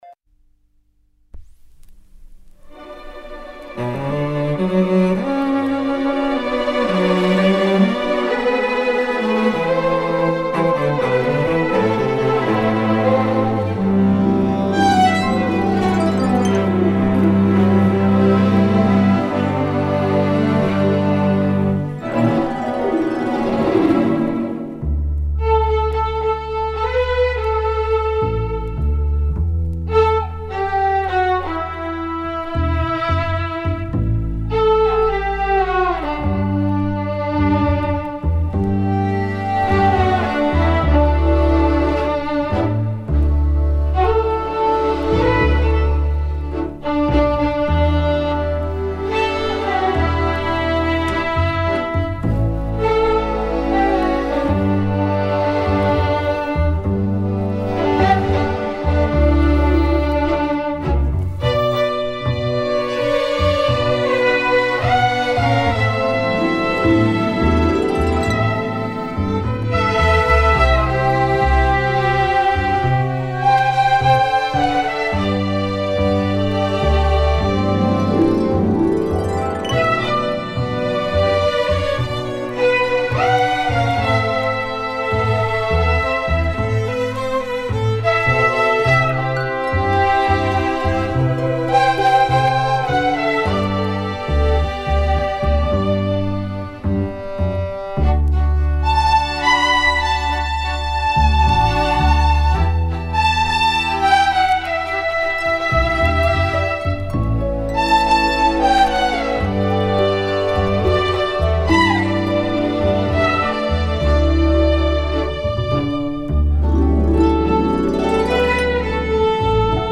Il sabato del villaggio... una trasmissione totalmente improvvisata ed emozionale. Musica a 360°, viva, legata e slegata dagli accadimenti.